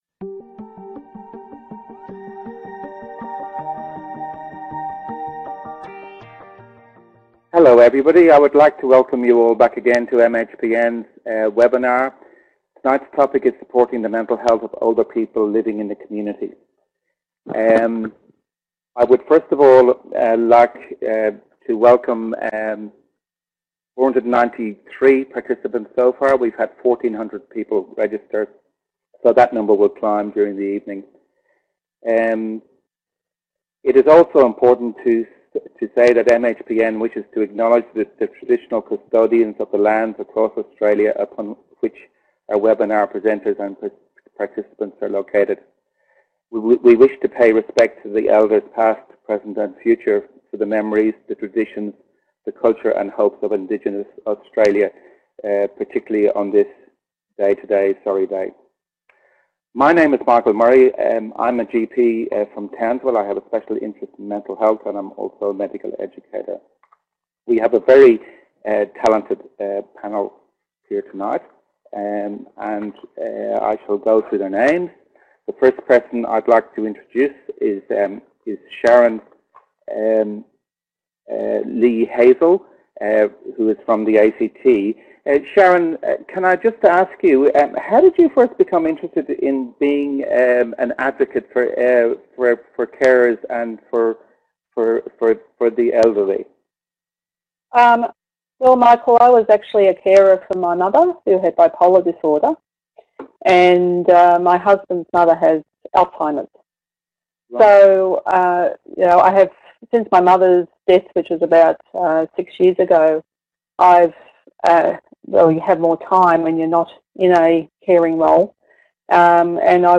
Join our interdisciplinary panel of experts for a discussion about how can we best support the mental health of older people living in the community.